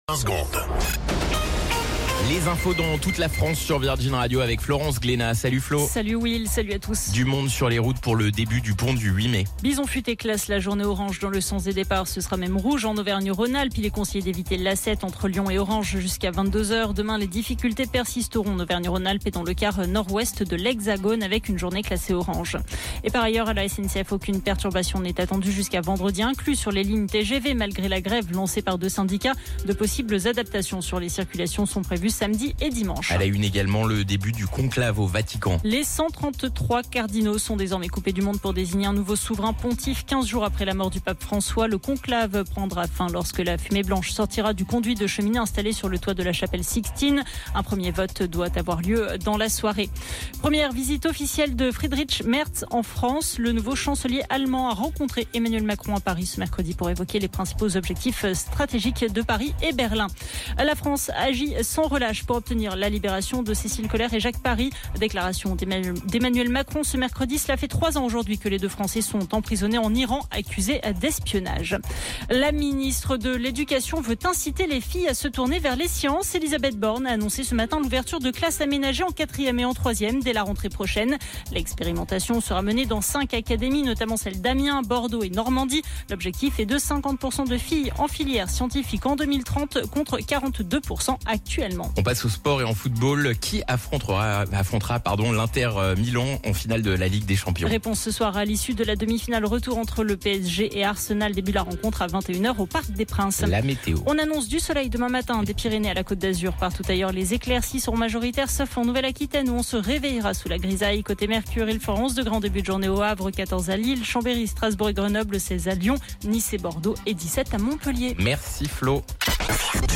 Flash Info National 07 Mai 2025 Du 07/05/2025 à 17h10 .